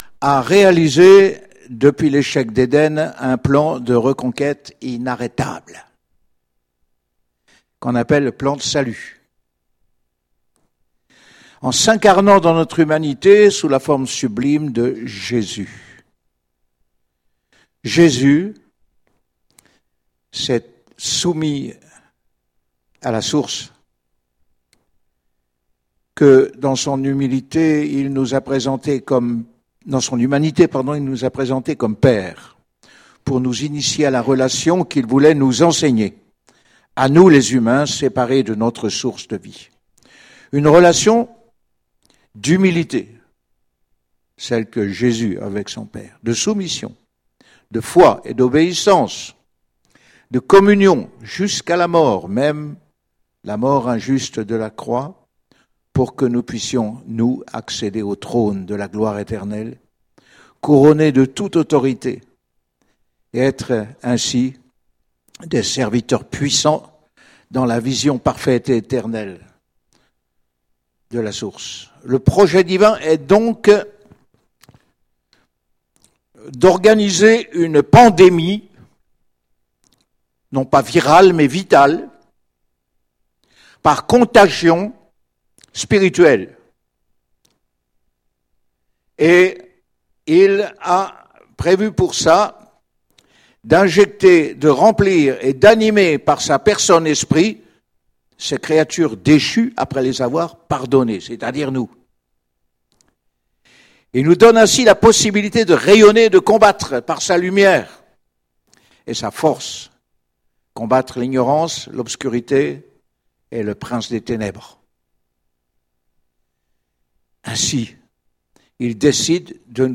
Marcher avec Jésus - Eglise Evangélique Aubagne